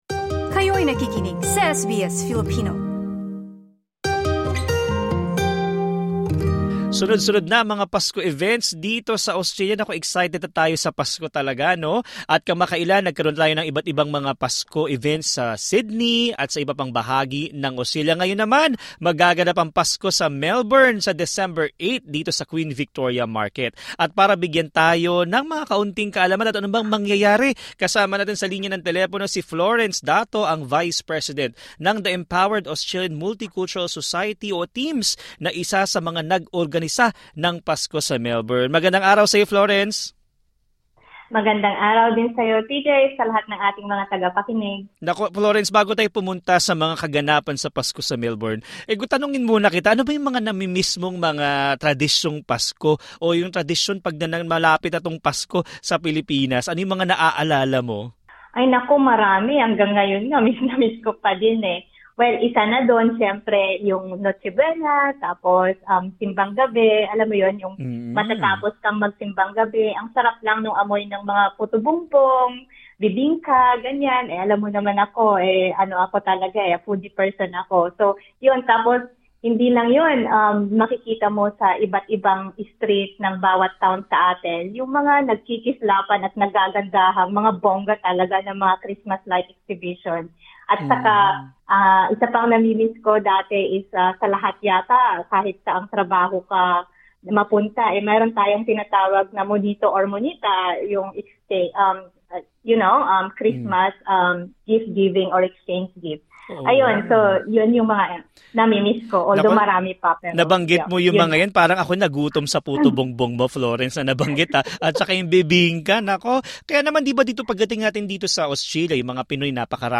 Sa panayam ng SBS Filipino